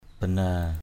/ba-na:/ (d.) động cơ = moteur. motor, engine.